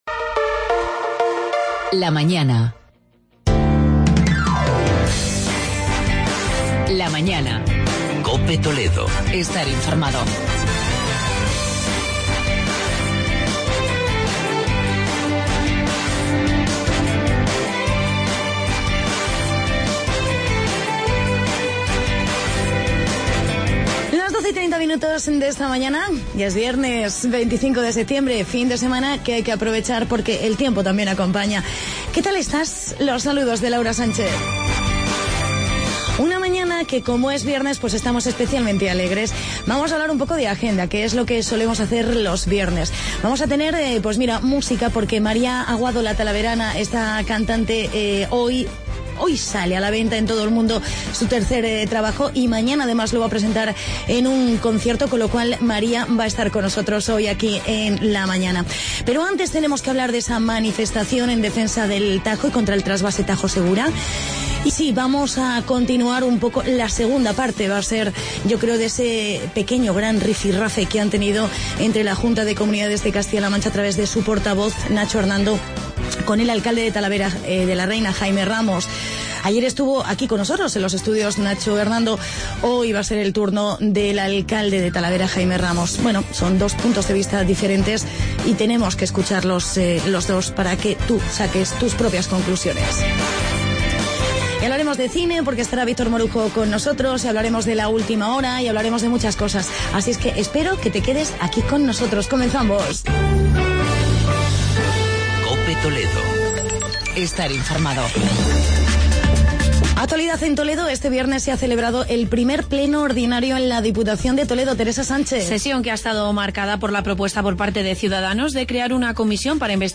Entrevista con el alcalde de Talavera, Jaime Ramos